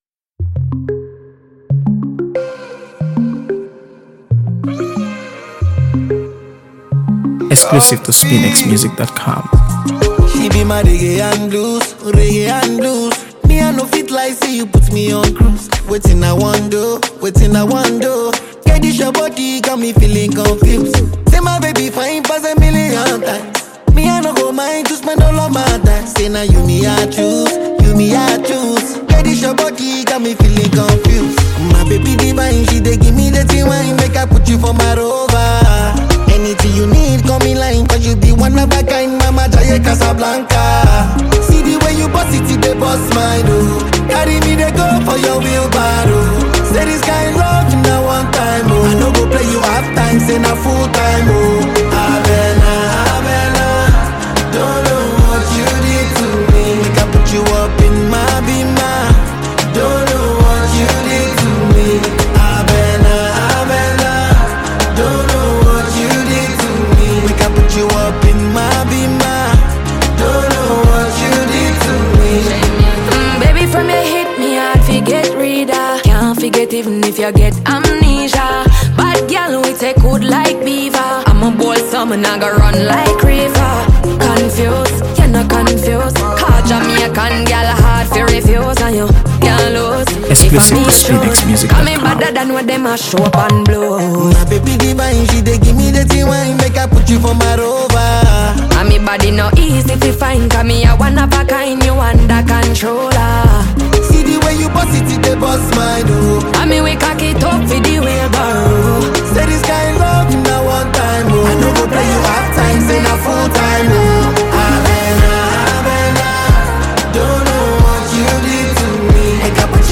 AfroBeats | AfroBeats songs
a sultry, melodic standout